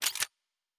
pgs/Assets/Audio/Sci-Fi Sounds/Weapons/Weapon 07 Reload 1.wav at 7452e70b8c5ad2f7daae623e1a952eb18c9caab4
Weapon 07 Reload 1.wav